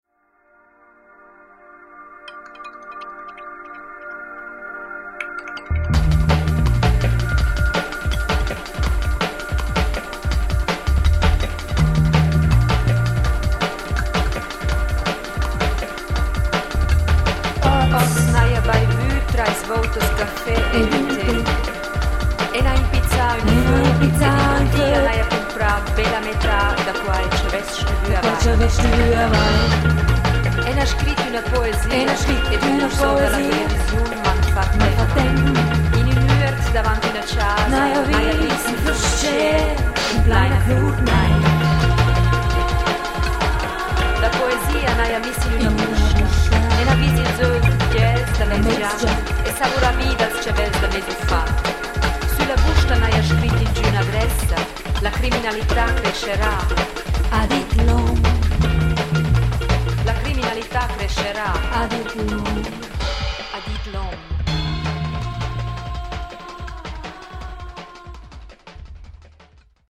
der im nächsten ausschnitt an den drums zu hören ist